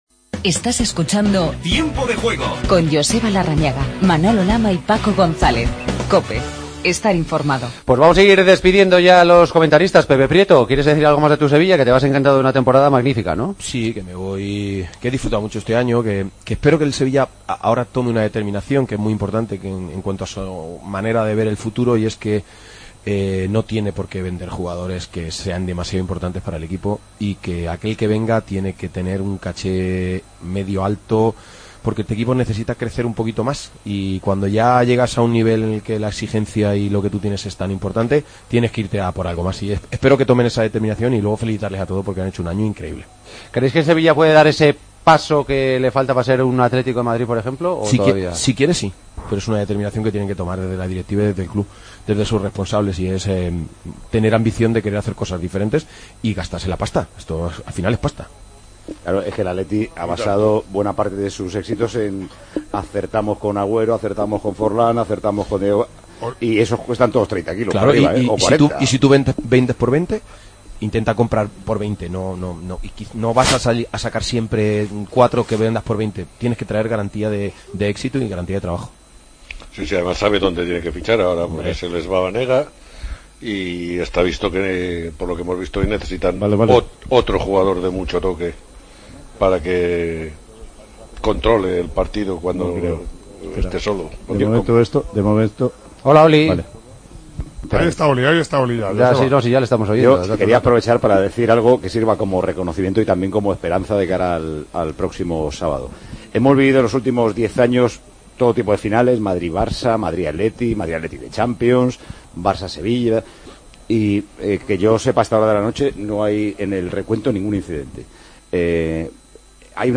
AUDIO: Continuamos con el tiempo de opinión. Entrevistamos al presidente del Barcelona Josep María Bartomeu.